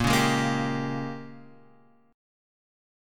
A# Minor 13th